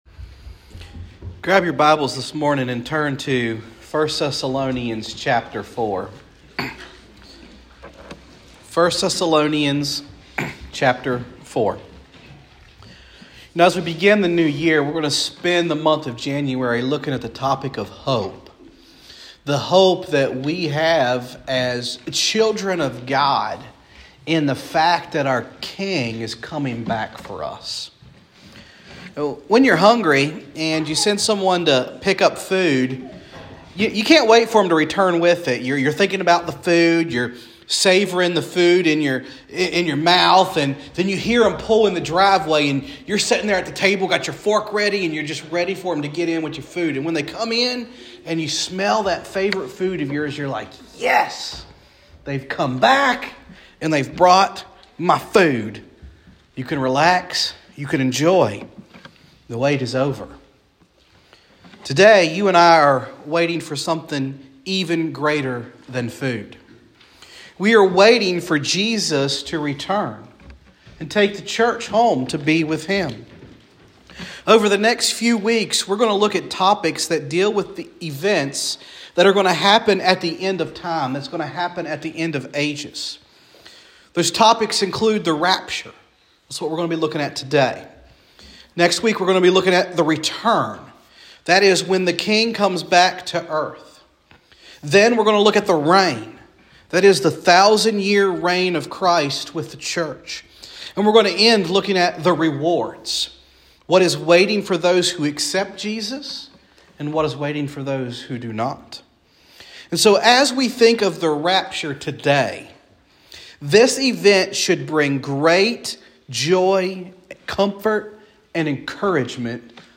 Sermons | Hopewell First Baptist Church
Guest Speaker